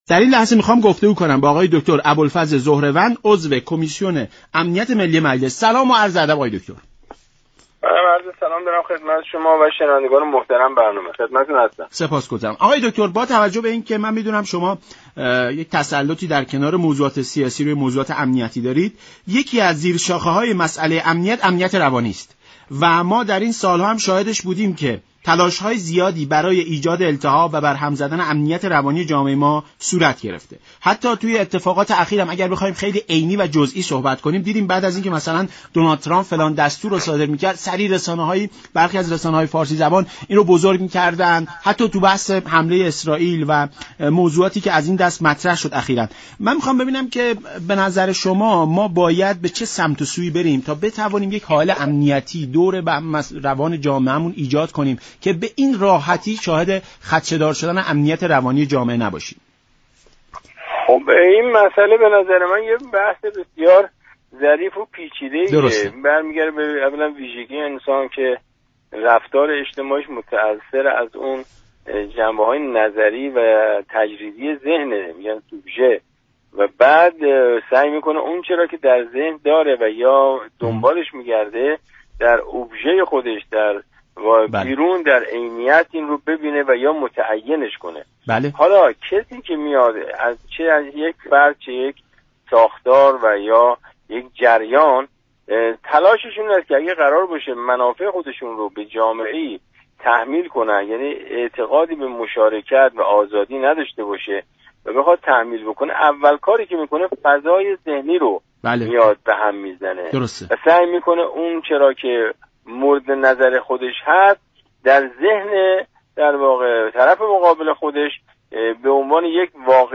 عضو كمیسیون امنیت ملی مجلس در برنامه ایران‌امروز گفت: سیاست‌های كلان كشور اگر توجه جامعه را به این سمت‌و‌سو بكشانند؛ كمتر دچار التهابات روانی می‌شوند.